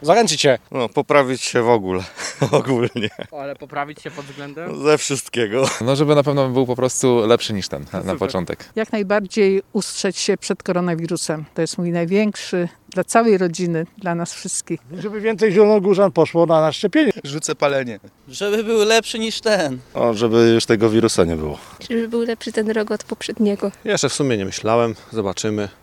Postanowienia noworoczne zielonogórzan [SONDA]
W związku z wejściem w nowy rok, zapytaliśmy zielonogórzan o tegoroczne postanowienia – zdecydowana większość stawia na lepsze przeżycie 2021 roku niż poprzedniego: